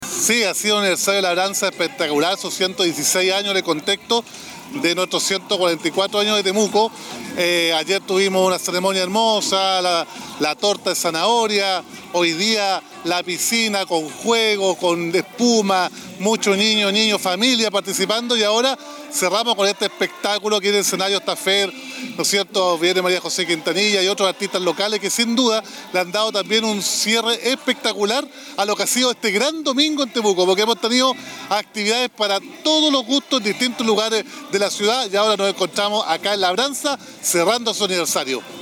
Roberto-Neira-Alcalde-de-Tmeuco.mp3